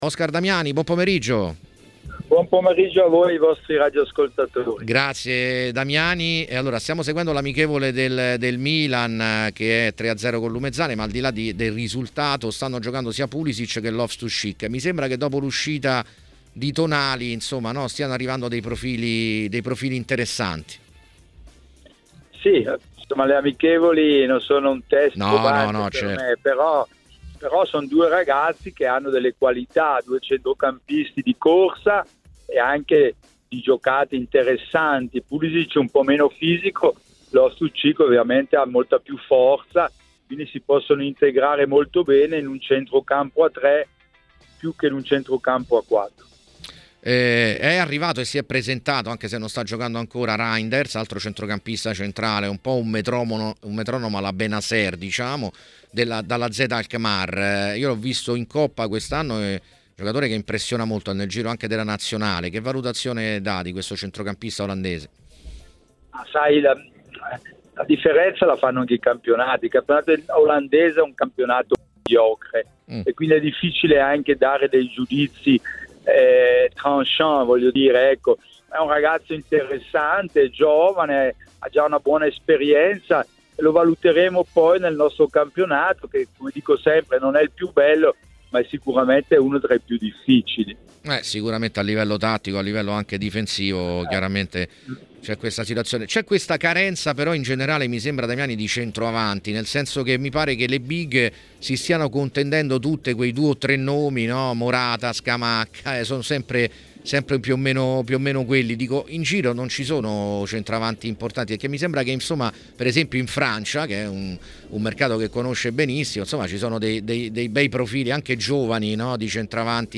Intervistato in esclusiva da TMW Radio, l'ex calciatore e agente Oscar Damiani ha formulato questa riflessione sull'assalto dell'Arabia Saudita ai campioni del calcio italiano ed europeo: "Capisco Marotta, che fa bei soldi con Brozovic che non avrebbe preso da altre parti.